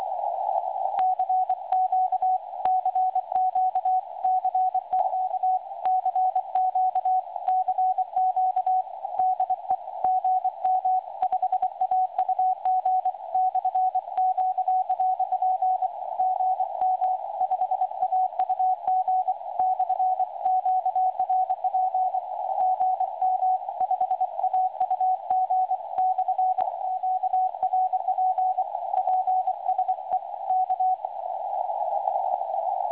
By way of a demonstration, below are recordings taken from various WebSDRs, showing how well a low power transmission can be heard around the world.